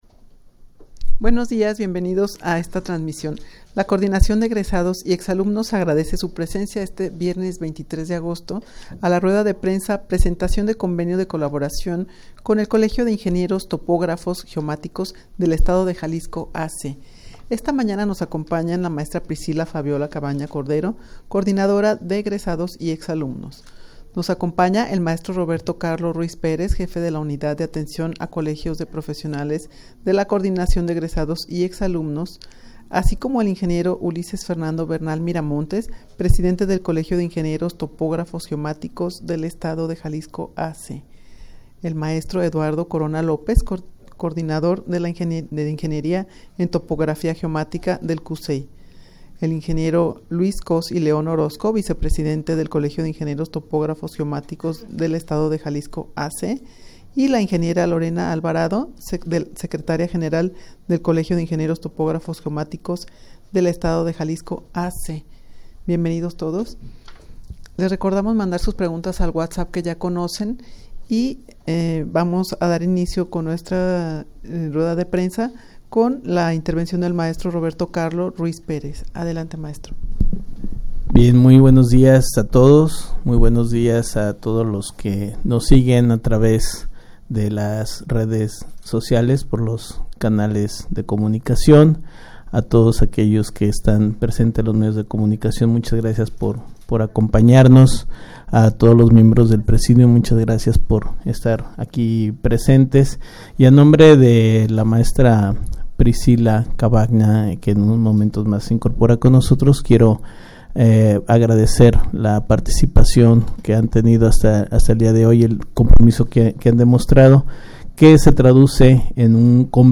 Audio de la Rueda de Prensa